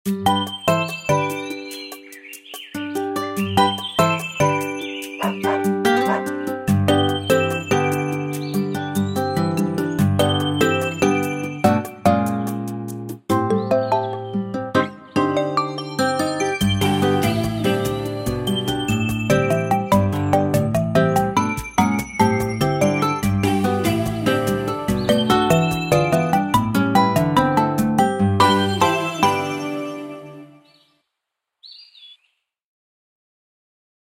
Спокойные И Тихие Рингтоны » # Рингтоны Без Слов